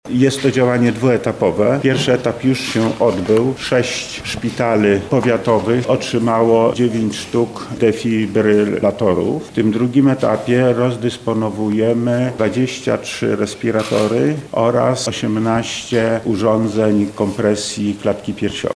Lech Sprawka– mówi Lech Sprawka, wojewoda lubelski.